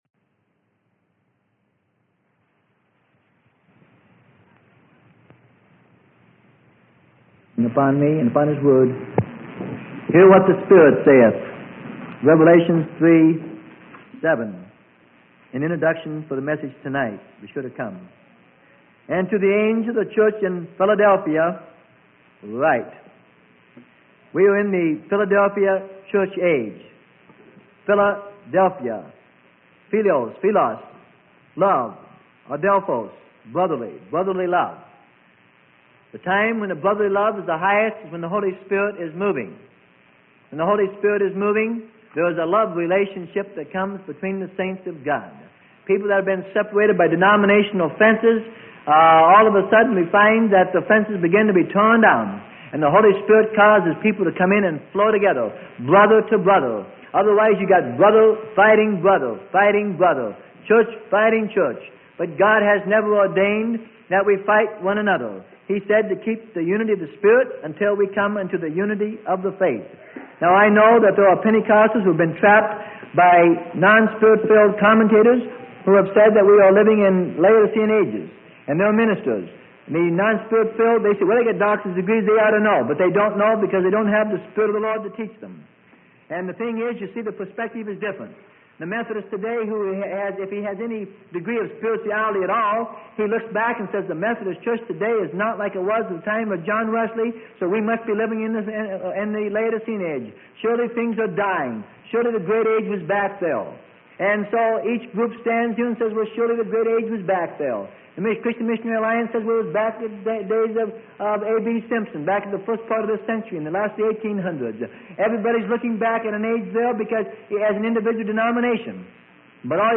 Sermon: Hear What the Spirit Saith - Part 1 - Freely Given Online Library